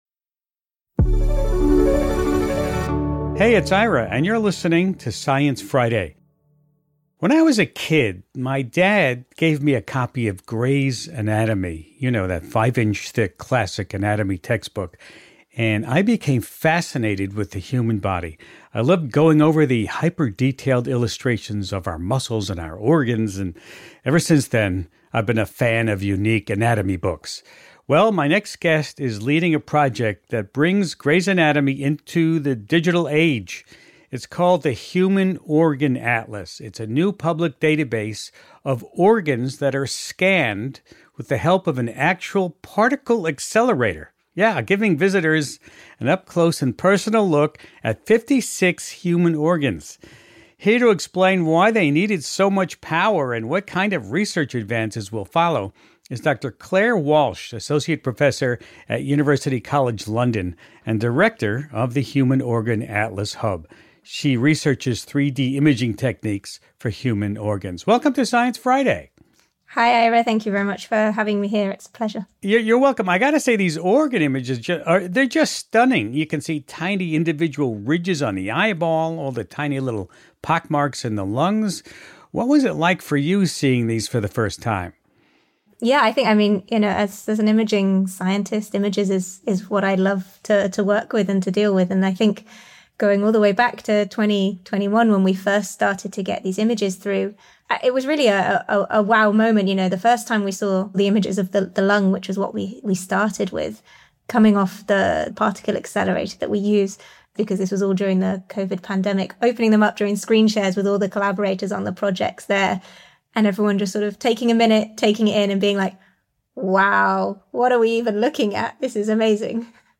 Joining Host Ira Flatow